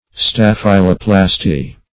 Search Result for " staphyloplasty" : The Collaborative International Dictionary of English v.0.48: Staphyloplasty \Staph"y*lo*plas`ty\ (st[a^]f"[i^]*l[-o]*pl[a^]s`t[y^]), n. [Gr.